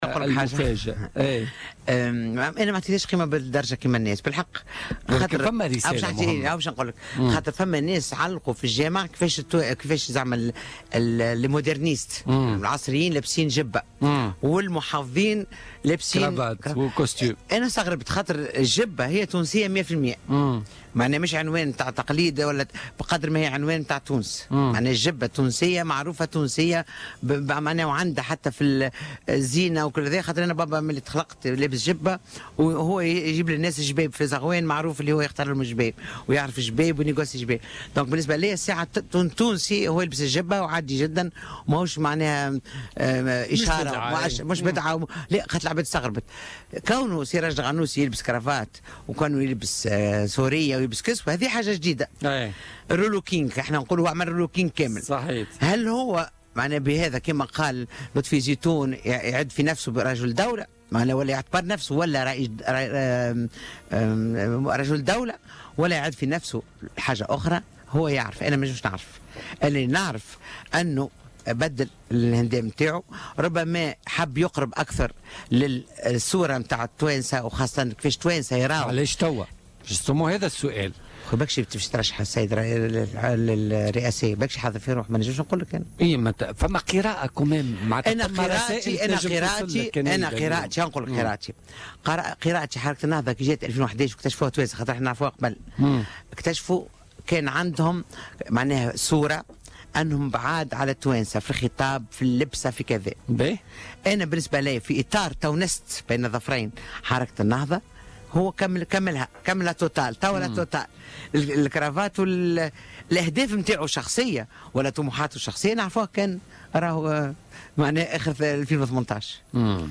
La députée et militante des droits de l’Homme, Bochra Belhaj Hmida, est revenue ce mardi 8 août 2017 sur Jawhara FM sur le port de la cravate par Rached Ghannouchi lors de son interview sur Nessma TV en commentant ce choix et sa signification politique.